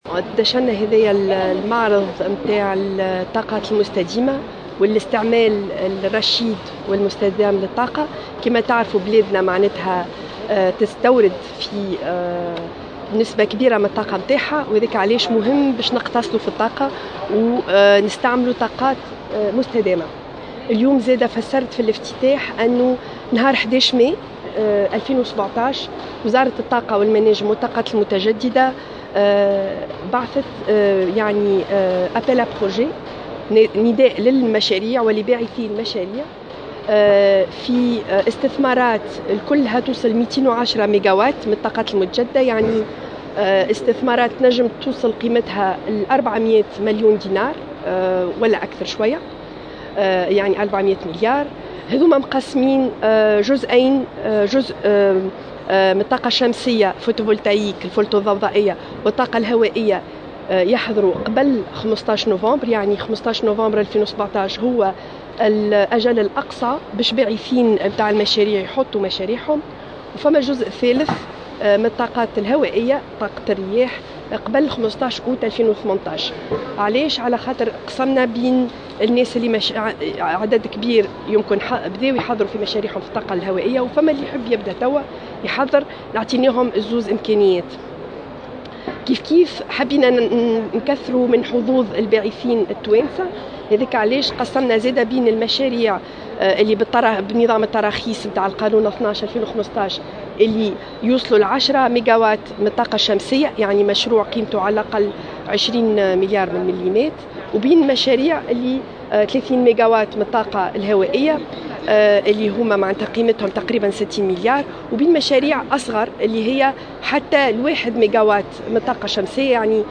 أشرفت وزيرة الطاقة والمناجم والطاقات المتجددة، هالة شيخ روحه، اليوم الأربعاء، على الافتتاح الرسمي للمعرض الدولي حول الطاقات المتجددة والتكييف والتسخين.